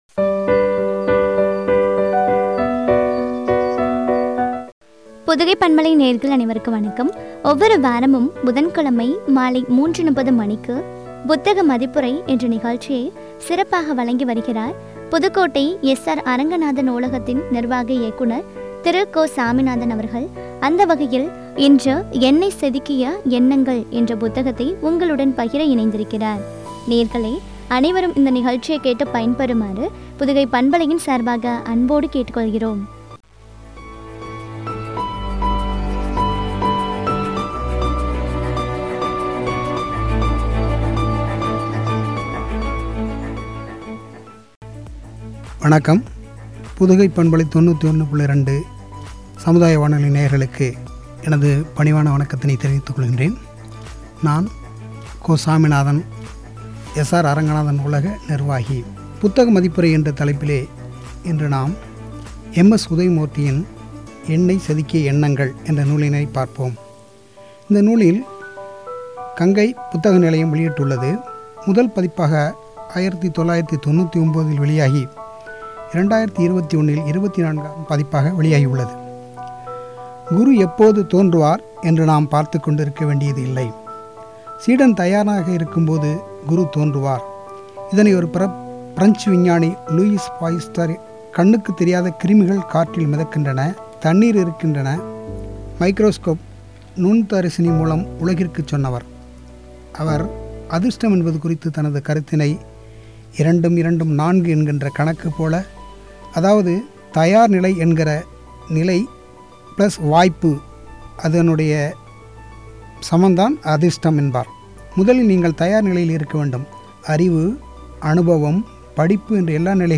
குறித்த வழங்கிய உரையாடல்.